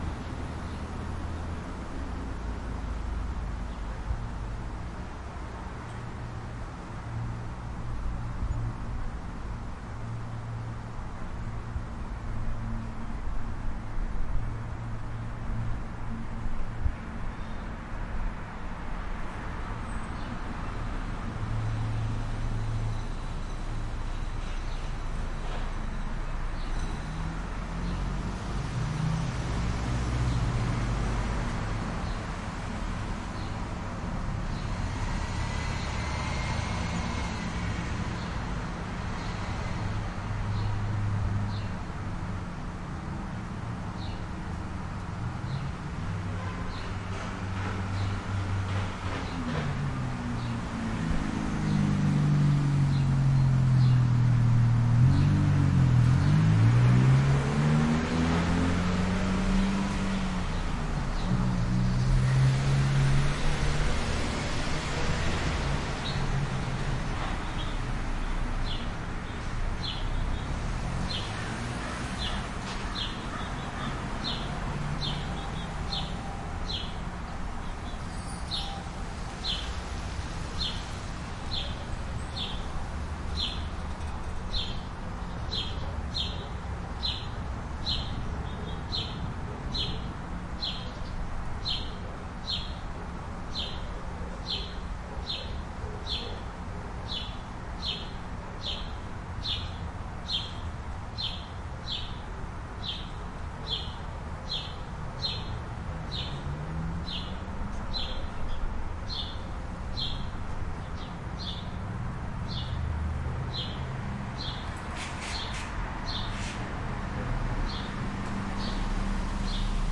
蒙特利尔》街灯交通鸟类汽车通过1凡尔登，蒙特利尔，魁北克
描述：路灯交通鸟车pass1凡尔登，蒙特利尔，魁北克.flac
Tag: 蒙特利尔 鸟类 传球 魁北克 交通 街道 汽车